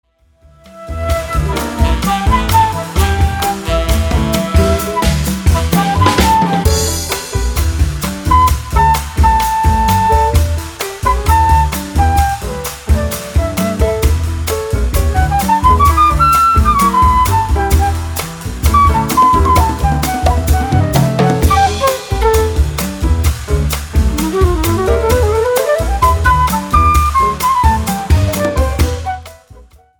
FUNKY SOUL  (03.27)